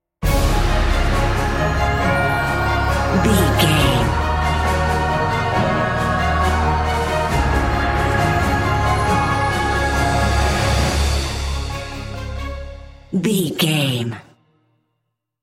Uplifting
Ionian/Major
C♯
Slow
brass
cello
horns
percussion
strings
violin